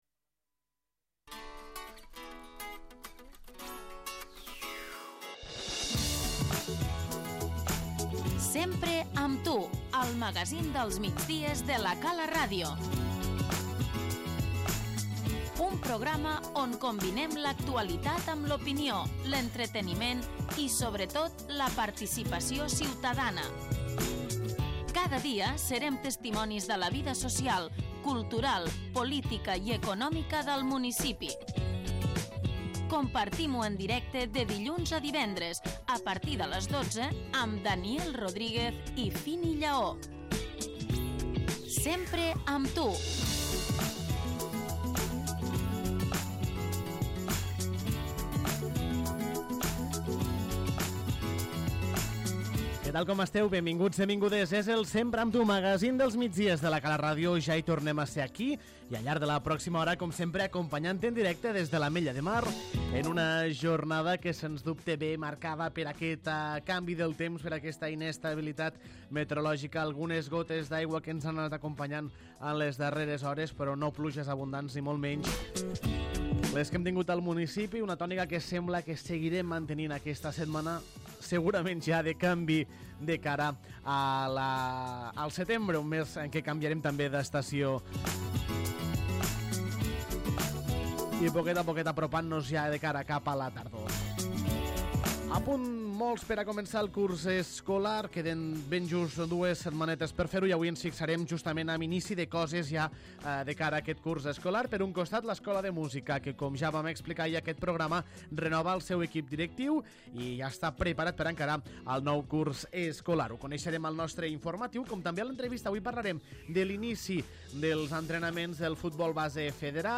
És dimarts 30 d'agost, i avui en l'edició 152 del magazín dels migdies de La Cala Ràdio, el Sempre amb tu, us hem explicat tot això: